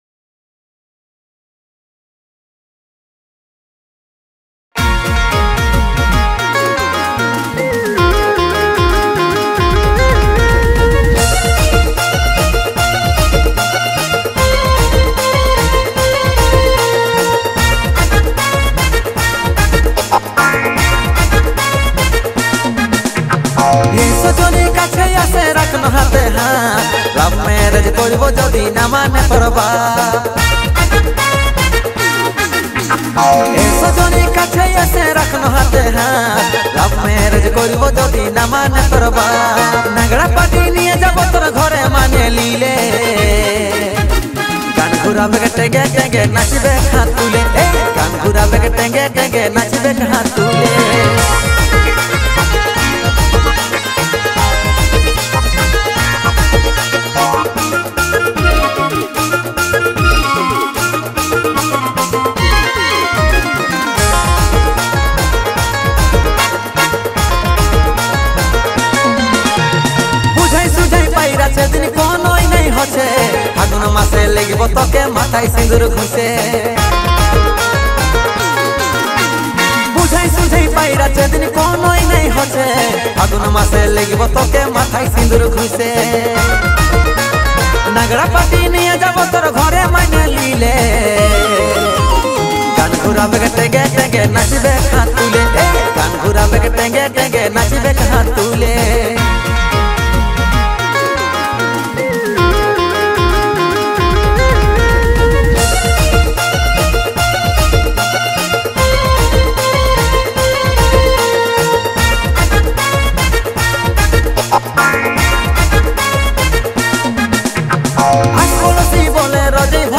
Releted Files Of Purulia Gana